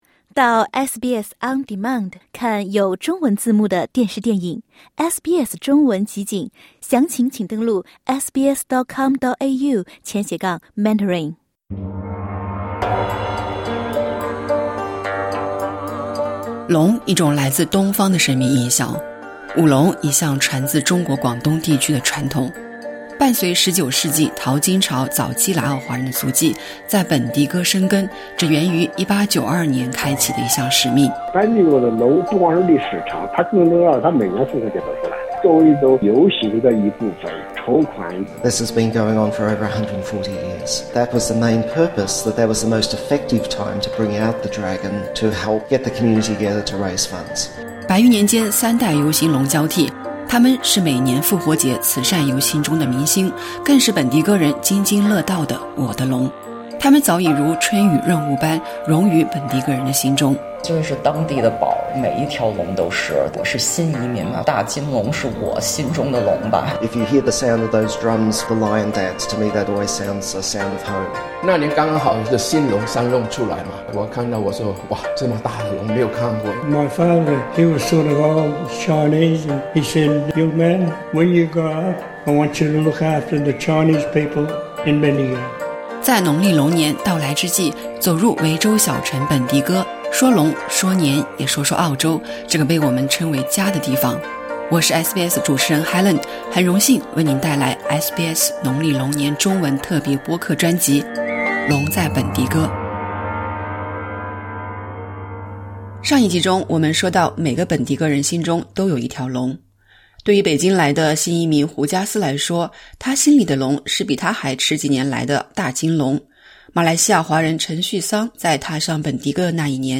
他们长居于此，心安即是家，而在农历新年阖家团圆之际，我在采访中聆听到了关于家与故乡的心声。